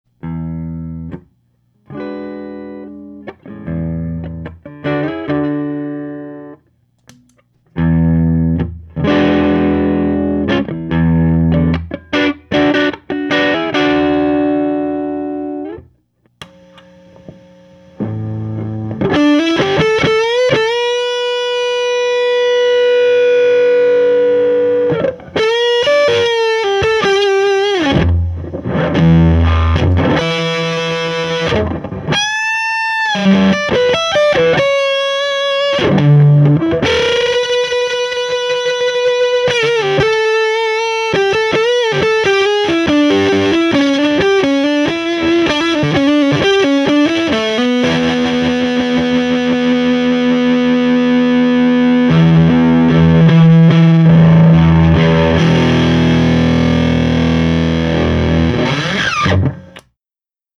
a little bit of break up/very loud lead
these samples were recorded using either an ibanez rg560 loaded with duncan hot rails and jb junior pickups or a crappy strat knockoff with unknown pickups running a homebrew single ended amp (12ax7 and 6550) and 12" openback cabinet. miced with a 57.